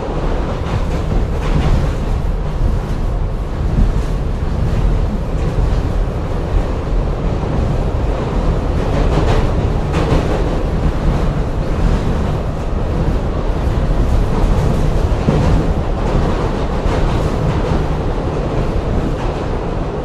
TrainMovingLoopSFX.wav